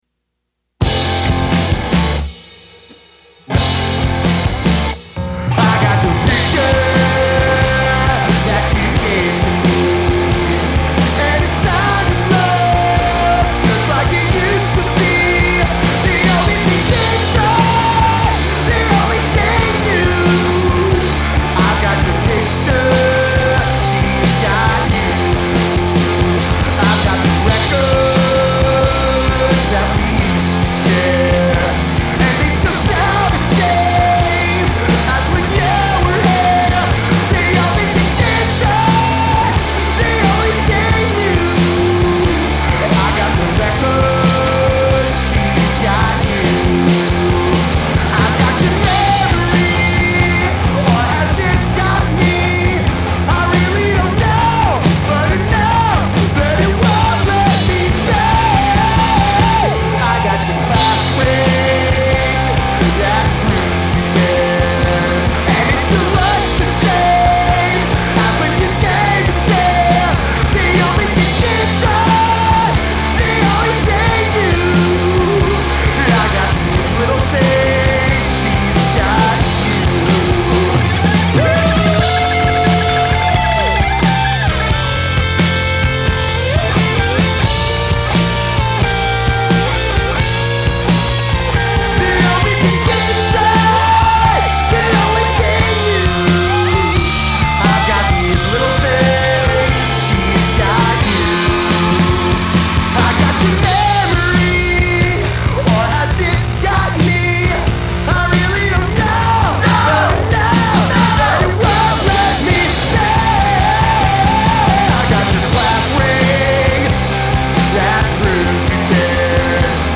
at The Space in Pittsburgh, PA